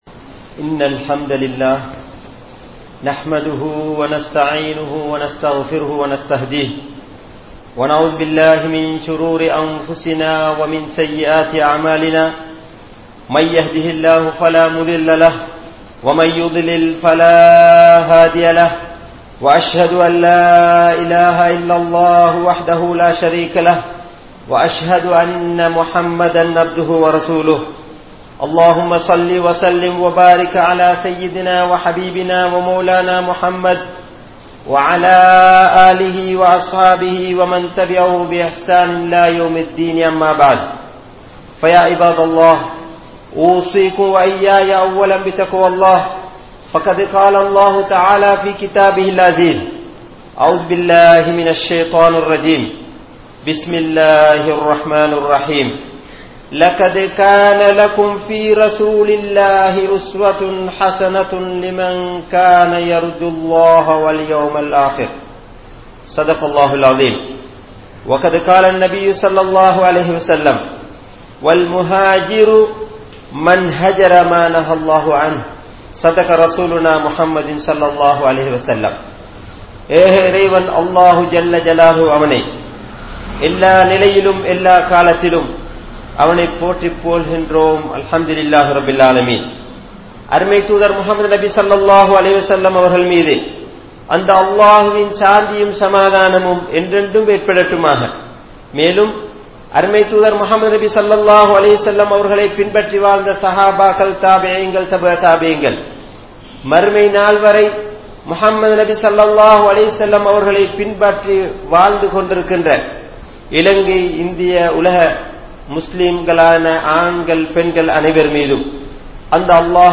Nabi(SAW)Avarhalin Mun Maathirihal (நபி(ஸல்)அவர்களின் முன்மாதிரிகள்) | Audio Bayans | All Ceylon Muslim Youth Community | Addalaichenai